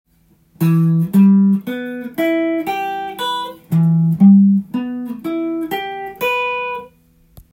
Emコードトーン